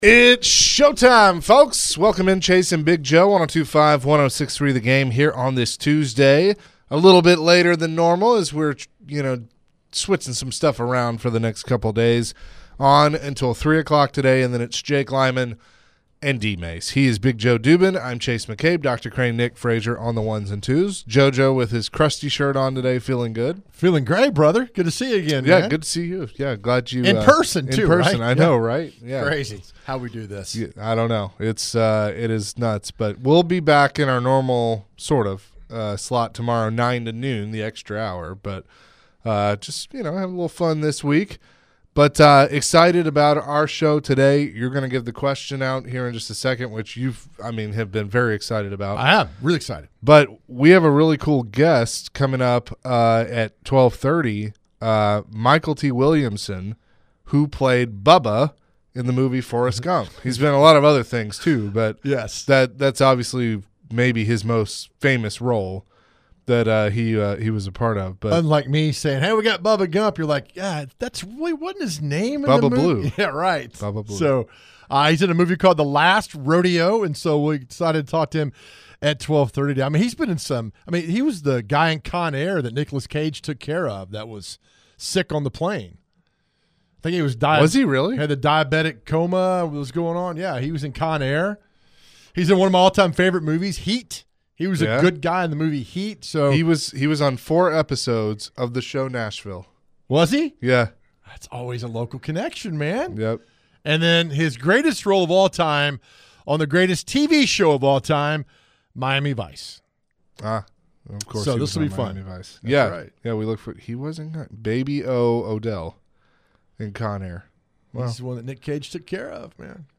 Later in the hour, the guys chatted with actor Mykelti Williamson.